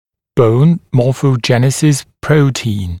[bəun ˌmɔːfəu’ʤenɪsɪs ‘prəutiːn][боун ˌмо:фоу’джэнисис ‘проути:н]белок морфогенеза кости